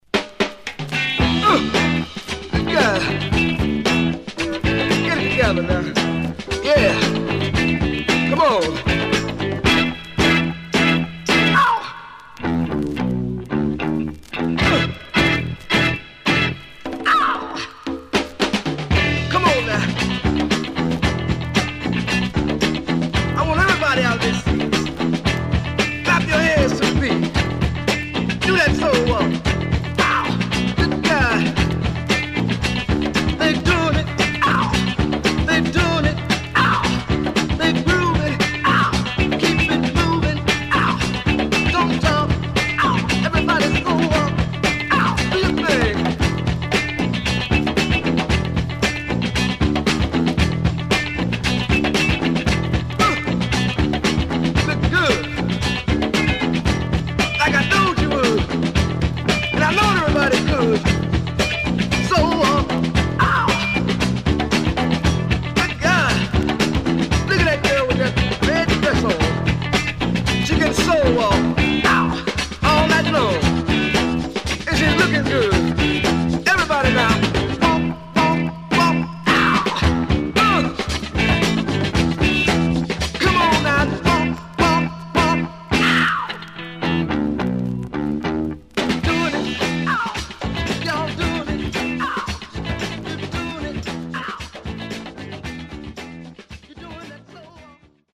Stereo/mono Mono
Folk